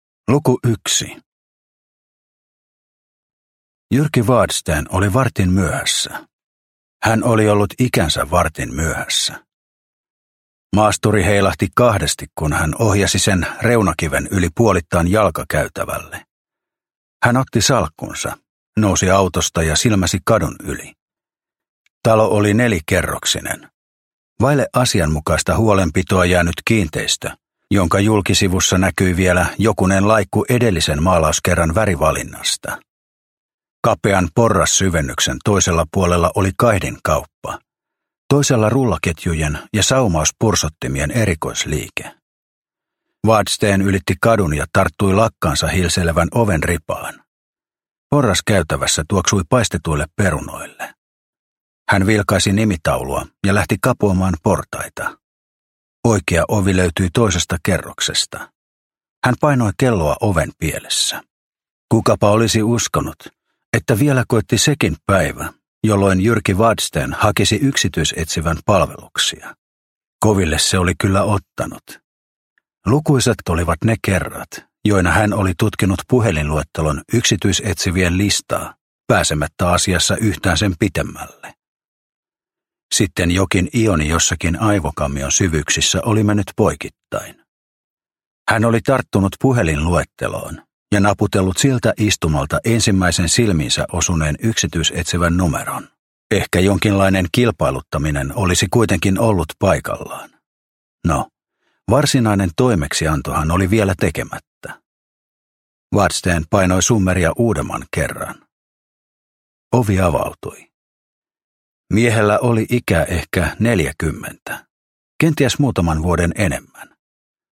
Kevätuhri – Ljudbok – Laddas ner